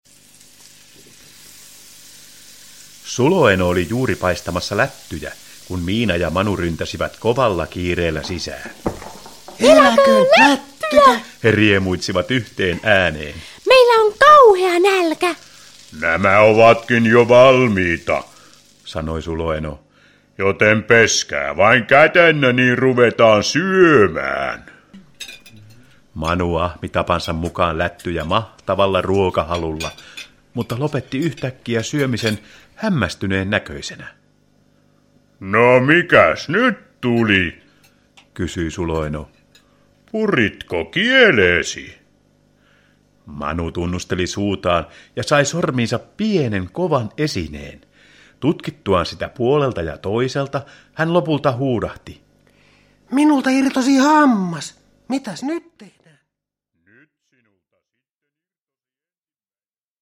Miina ja Manu hammaslääkärissä – Ljudbok – Laddas ner